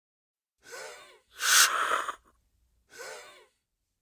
Inhaler Wheeze and Use
SFX
yt_uLcTBpSiUGg_inhaler_wheeze_and_use.mp3